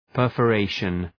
Προφορά
{,pɜ:rfə’reıʃən}